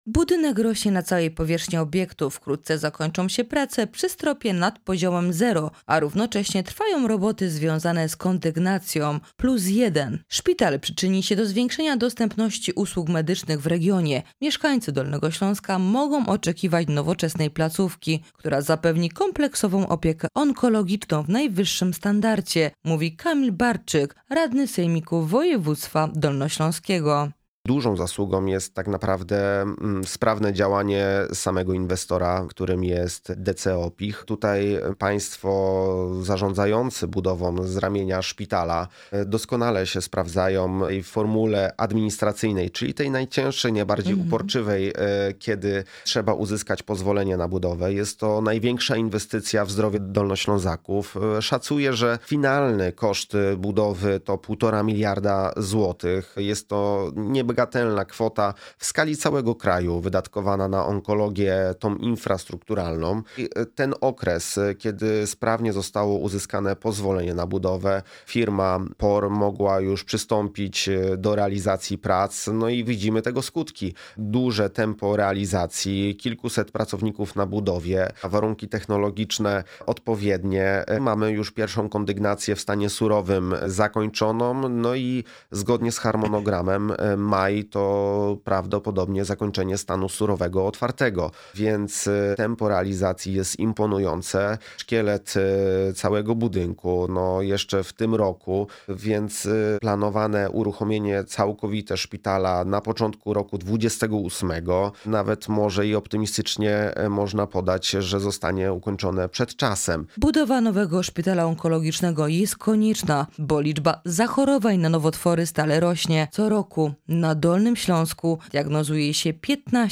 Radny Sejmiku Kamil Barczyk w audycji „Dolny Śląsk z bliska”
Jest nim Kamil Barczyk radny Sejmiku Województwa Dolnośląskiego (Klub Radnych: Trzecia Droga – Polskie Stronnictwo Ludowe).